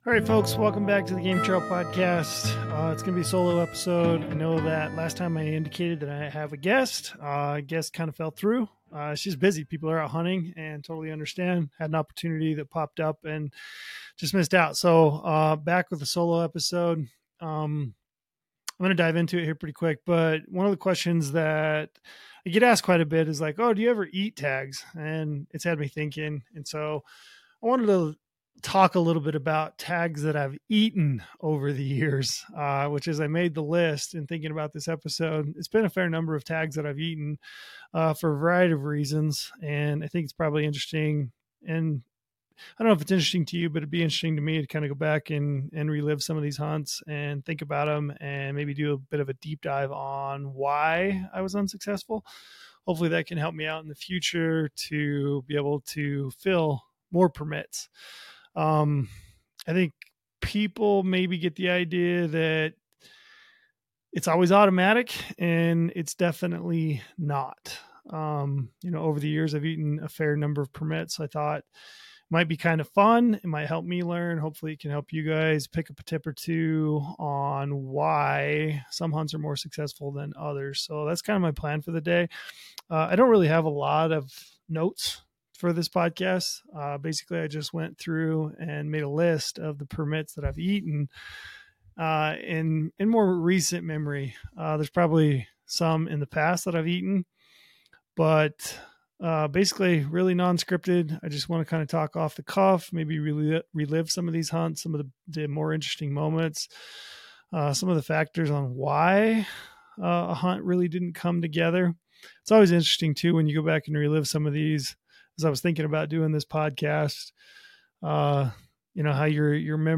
In this solo episode